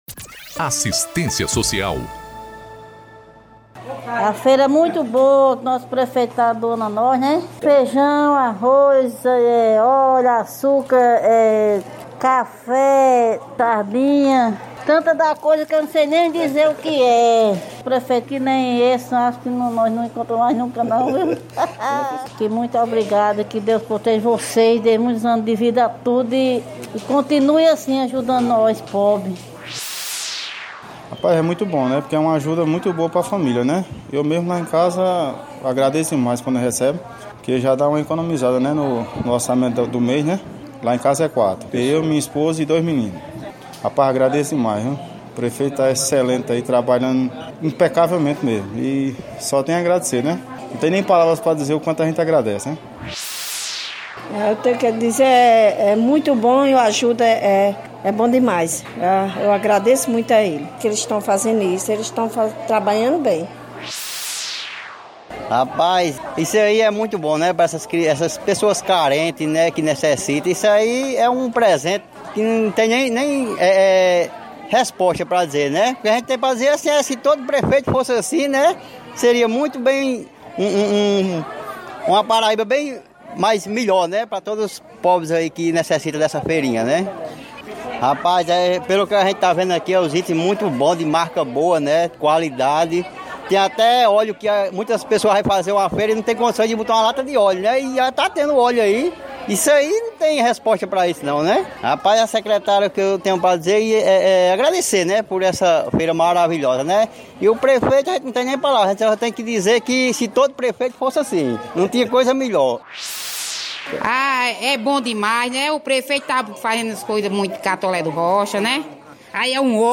Ouçam alguns beneficiados:
10-Entrega-de-300-Cestas-Basicas-Beneficiados-agradecimentos.mp3